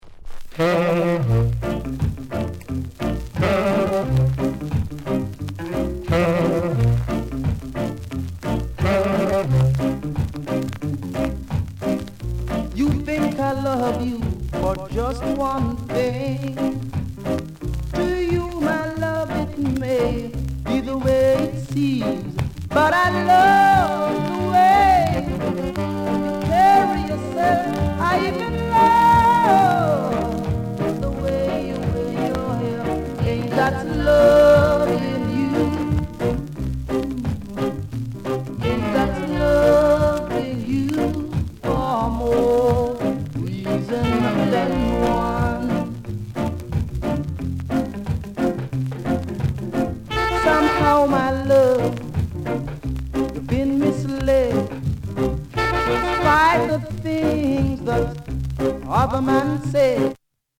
NICE ROCKSTEADY INST